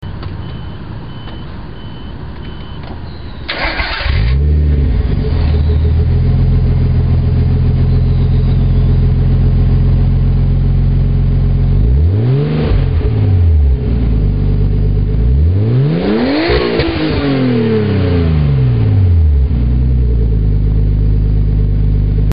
若干音質が劣化してますがご勘弁を（；−−）ﾉ
レベル１が静かな方で、逆にレベル５が爆音です。
登録No． パーツの種類 マフラー
音量 レベル２
・インナーサイレンサー無し。